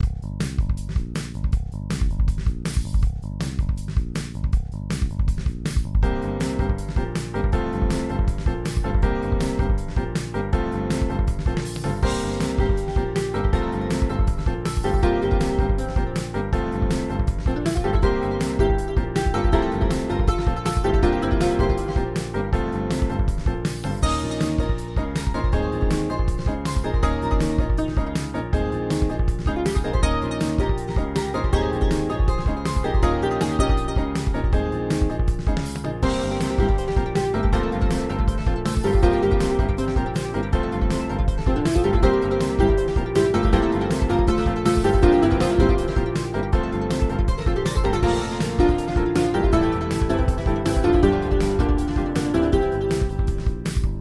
A groovy, driving song.
• Music requires/does smooth looping